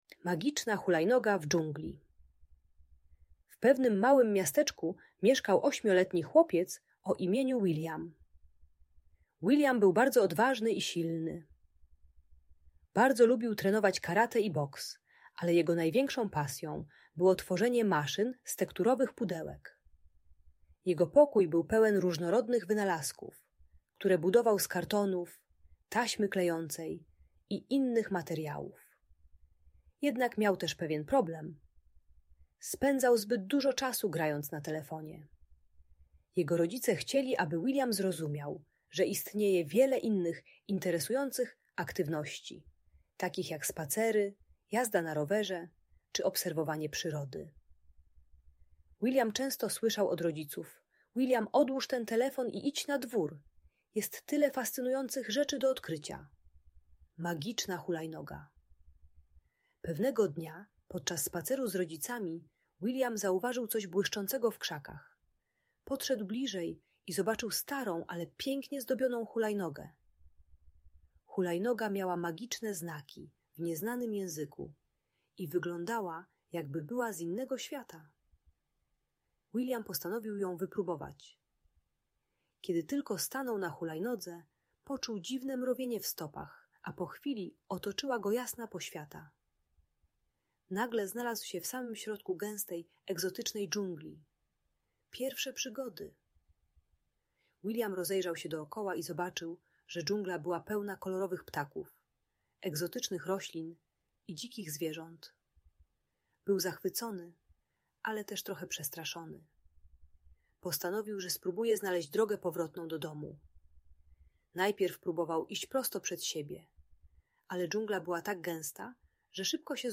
Magiczna Hulajnoga w Dżungli - Audiobajka